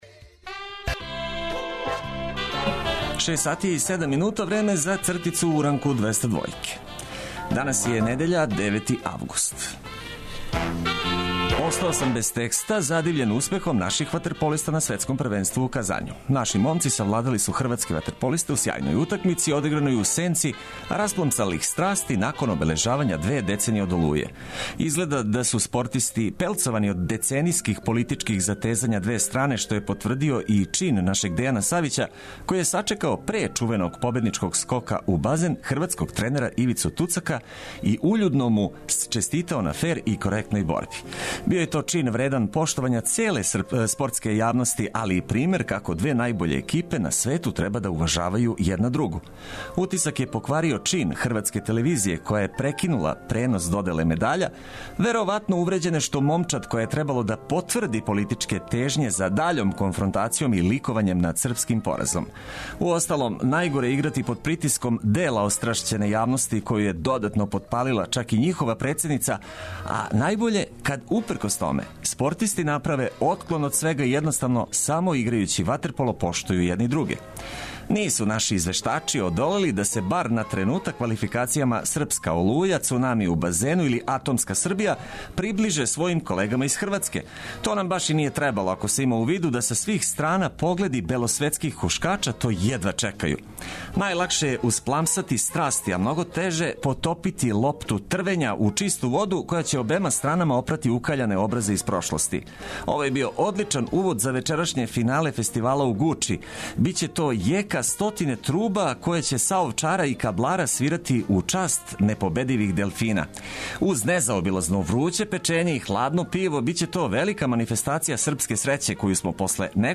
Недељно јутро започните уз 202 осмеха и ведре тонове одличне музике за буђење освежени добрим вибрацијама које ћемо емитовати упркос жарком лету.